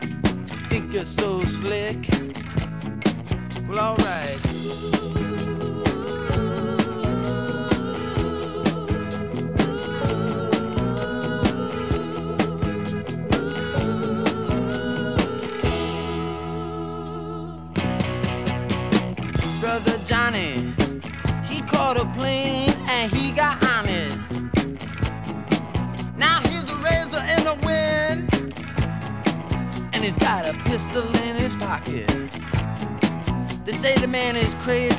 Жанр: Панк